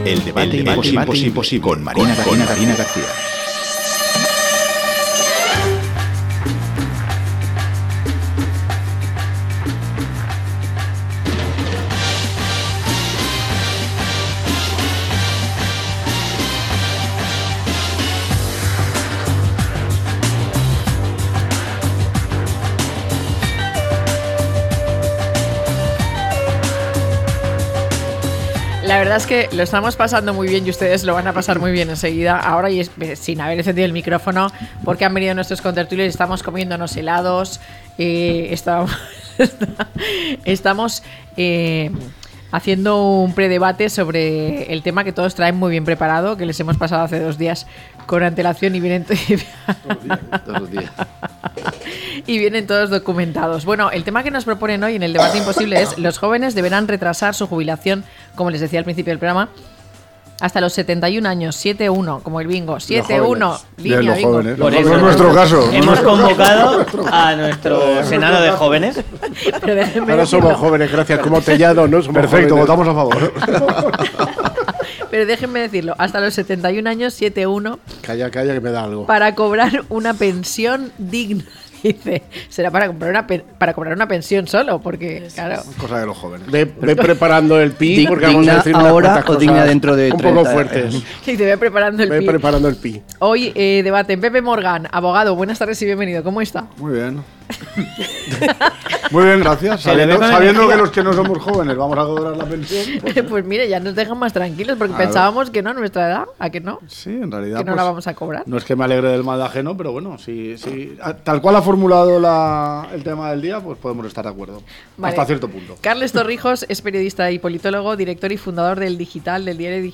El futuro de las pensiones en España, a debate - La tarde con Marina
0703-LTCM-DEBATE.mp3